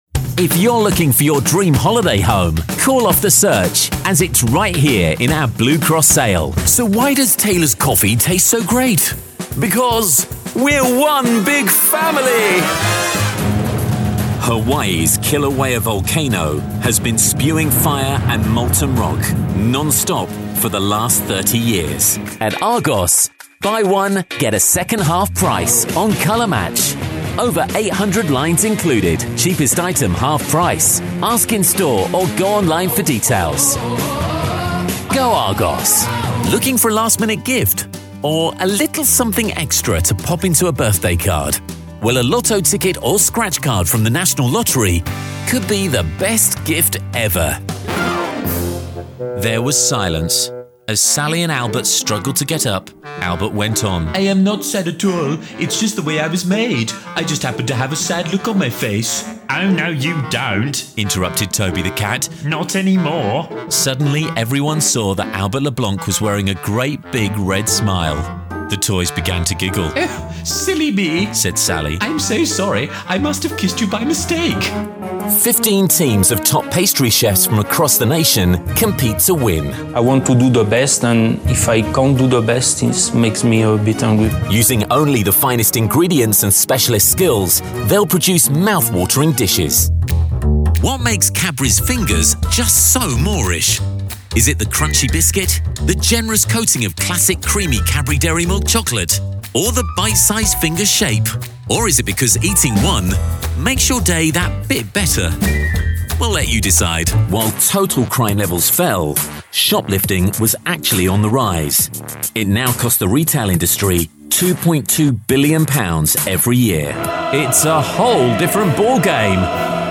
Voice Over Showreel